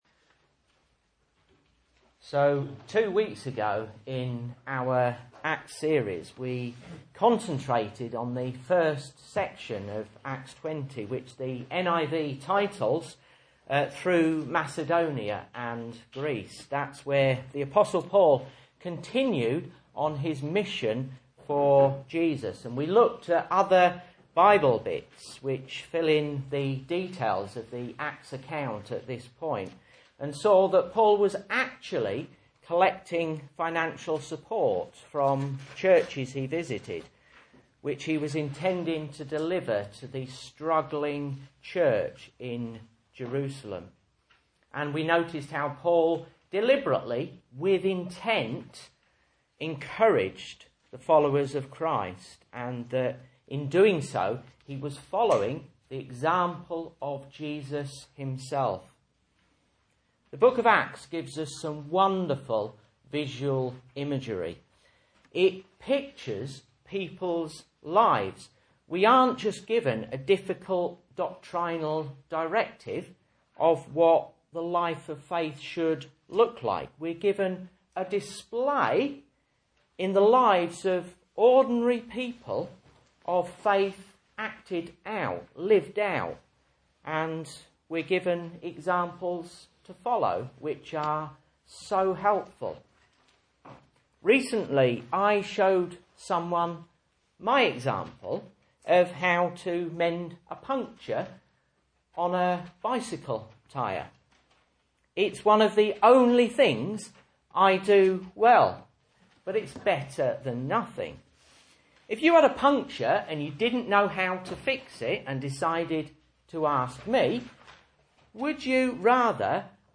Message Scripture: Acts 20:6-12 | Listen